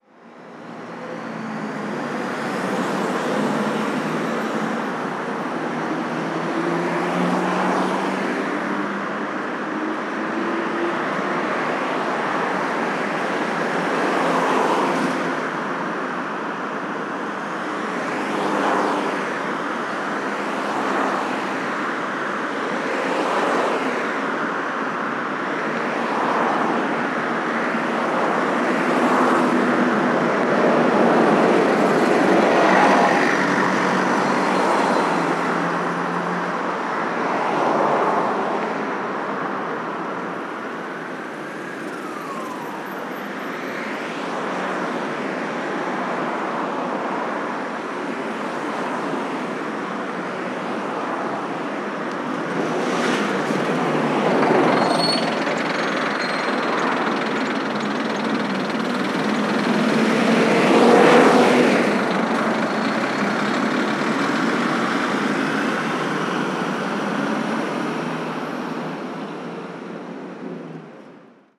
Ambiente del Paseo de la Castellana, Madrid
tráfico
acelerar
moto
motor
sirena
Sonidos: Ciudad